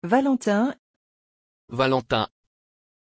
Pronunciation of Vallentin
pronunciation-vallentin-fr.mp3